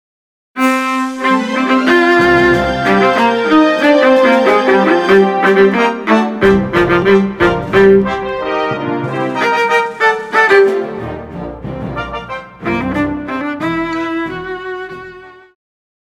Pop
Viola
Orchestra
Instrumental
World Music,Fusion
Only backing